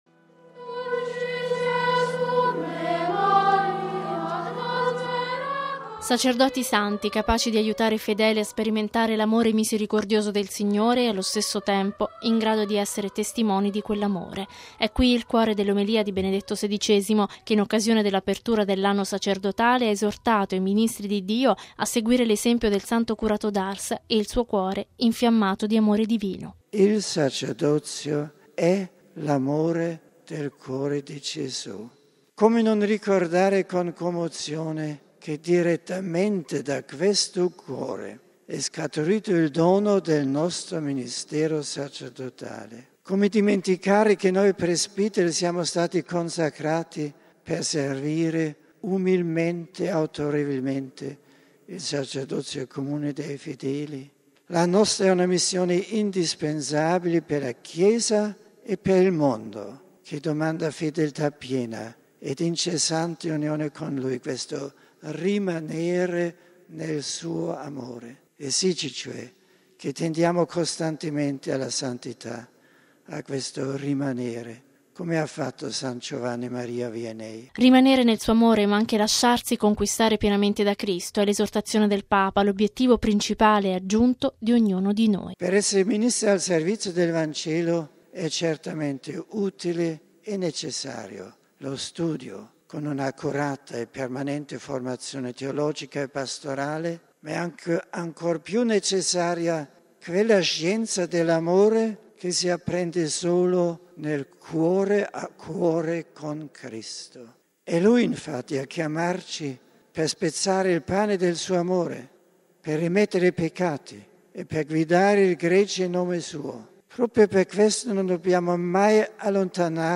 E’ uno dei passaggi dell’omelia di Benedetto XVI in occasione della Celebrazione dei secondi Vespri, ieri sera nella Basilica Vaticana, nella Solennità del Sacratissimo Cuore di Gesù.